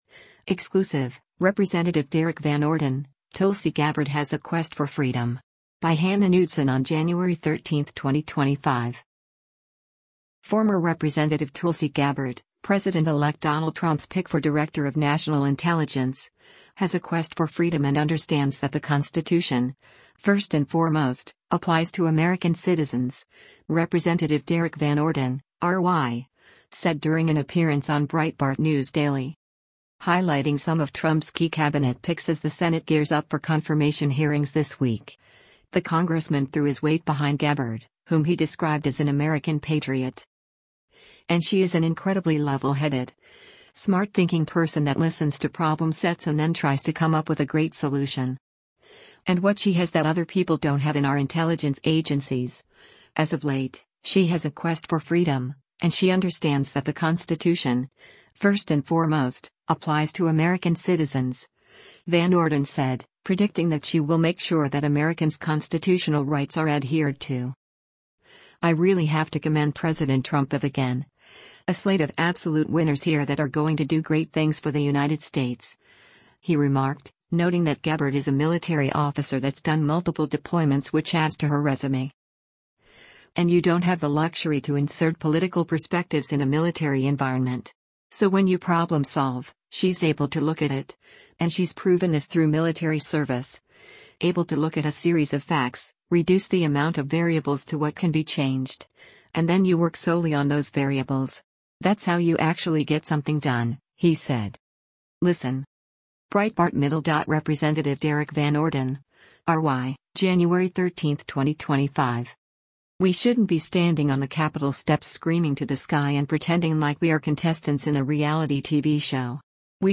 Former Rep. Tulsi Gabbard, President-elect Donald Trump’s pick for Director of National Intelligence, has a “quest for freedom” and “understands that the Constitution, first and foremost, applies to American citizens,” Rep. Derrick Van Orden (R-WI) said during an appearance on Breitbart News Daily.